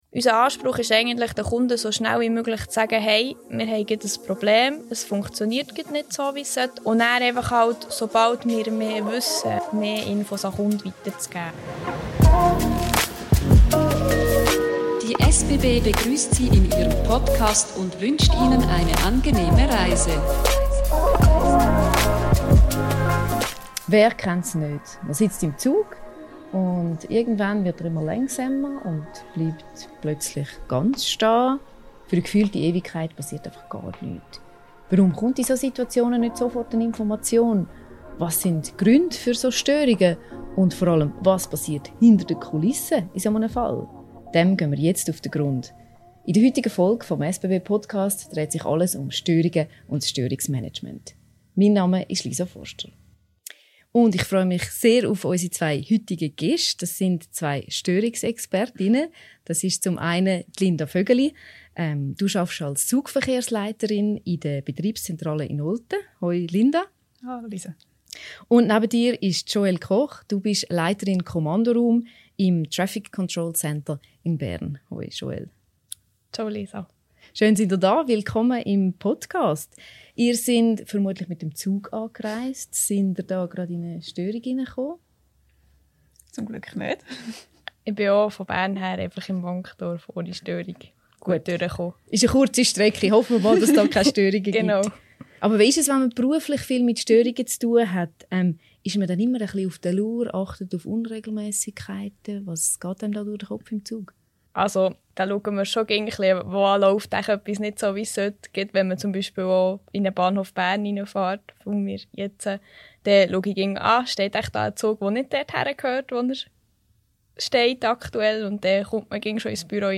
| SBB News Betriebslage & Störungen | SBB Apropos Störungen: Das Hintergrundgeräusch, das ihr stellenweise hört, stammt von einer Baustelle nebenan.